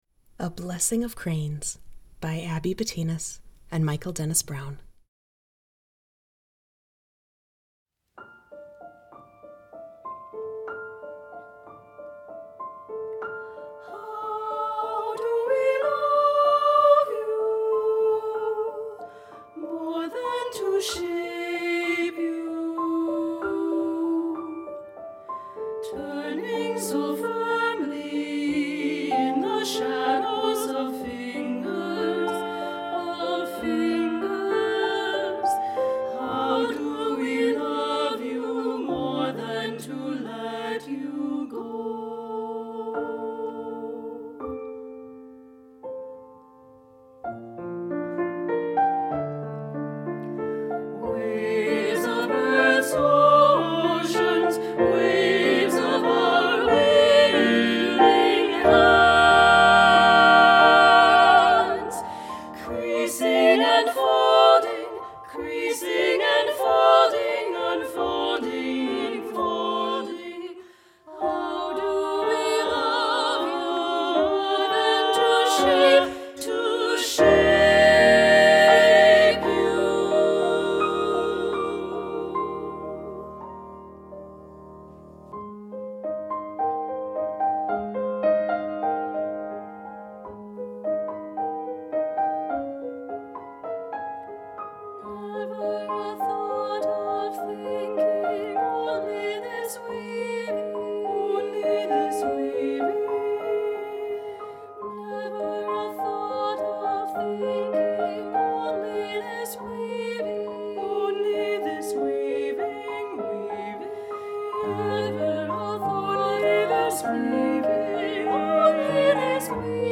SSAA chorus, piano